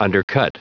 Prononciation du mot undercut en anglais (fichier audio)
Prononciation du mot : undercut